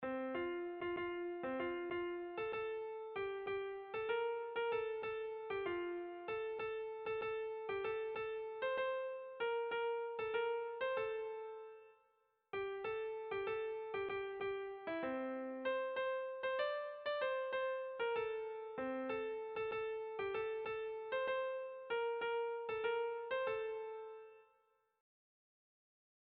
Air de bertsos - Voir fiche   Pour savoir plus sur cette section
Irrizkoa
Zortziko txikia (hg) / Lau puntuko txikia (ip)
ABDB